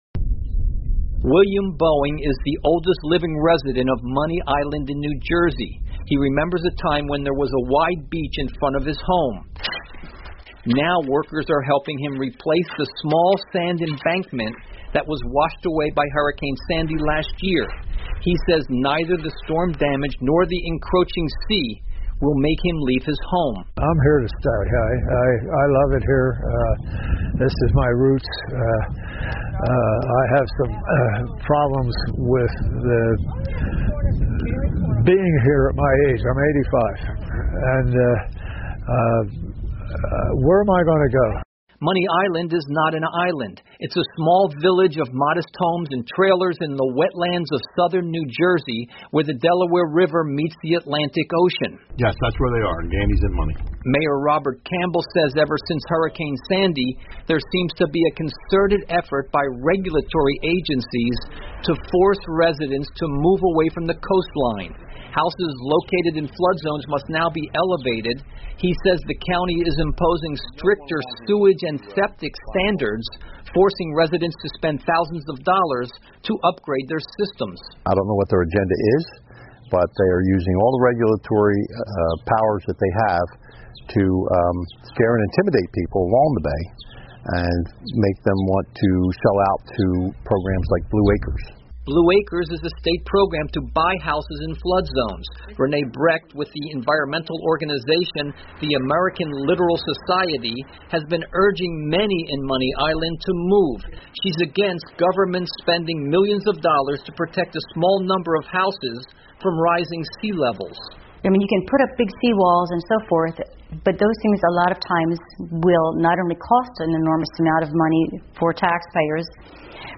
VOA常速英语2013 海平面上升策促使新泽西居民迁入内陆 听力文件下载—在线英语听力室